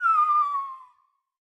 bombWhistle.ogg